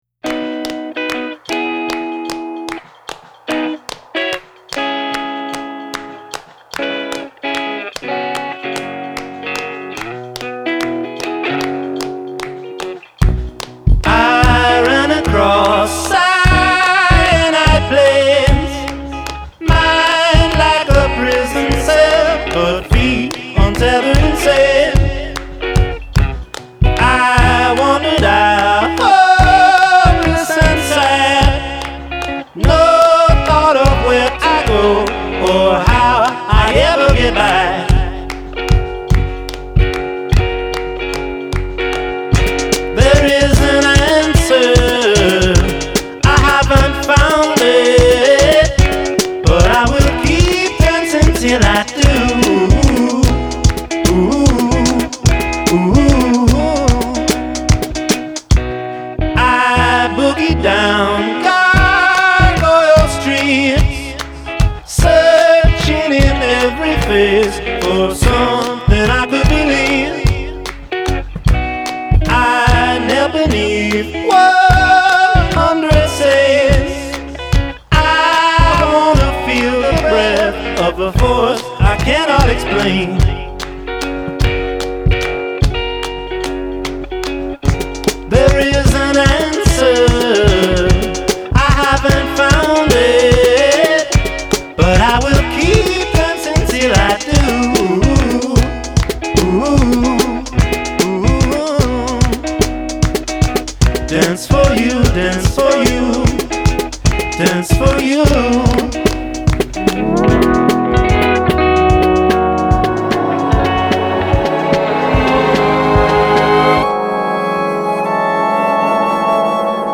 are tripped up with hand claps
along with echoing reverb and delicacy.